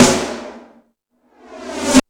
Hip House(04).wav